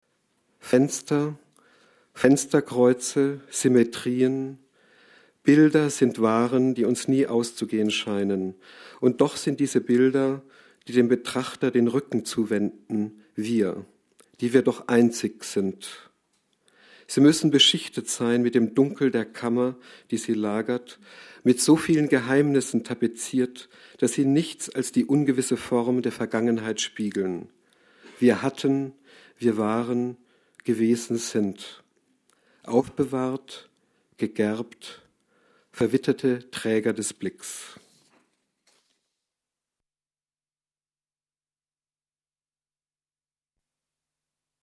Lesung von Joachim Sartorius in der literaturWERKstatt Berlin zur Sommernacht der Lyrik – Gedichte von heute